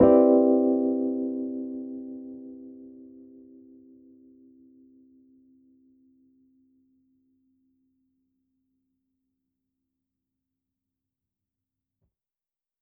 JK_ElPiano3_Chord-Cmaj9.wav